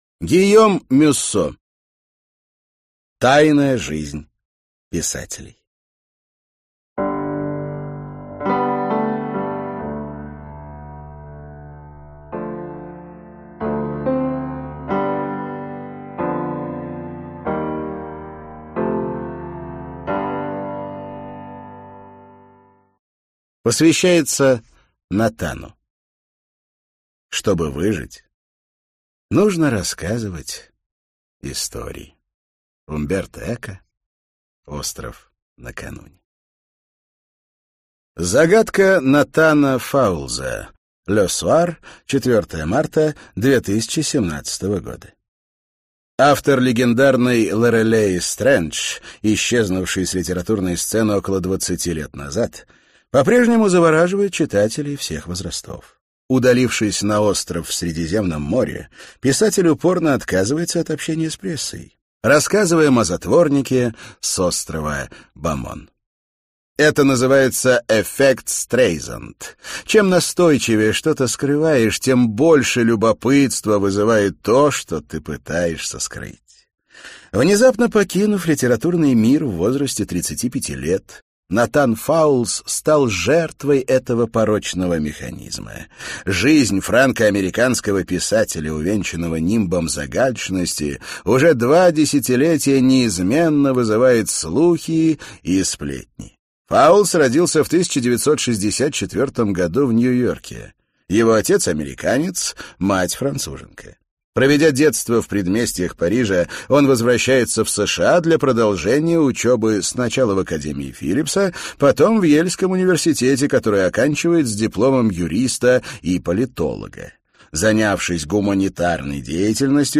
Аудиокнига Тайная жизнь писателей - купить, скачать и слушать онлайн | КнигоПоиск